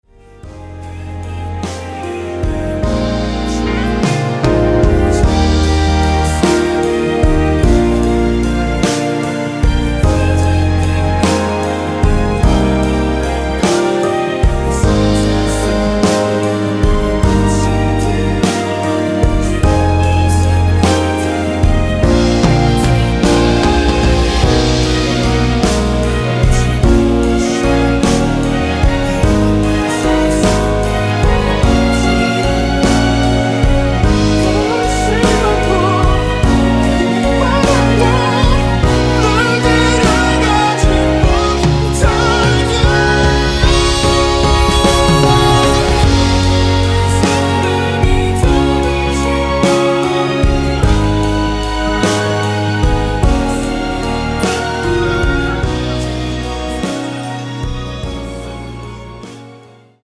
원키에서(-2)내린 멜로디와 코러스 포함된 MR입니다.(미리듣기 확인)
Eb
앞부분30초, 뒷부분30초씩 편집해서 올려 드리고 있습니다.
중간에 음이 끈어지고 다시 나오는 이유는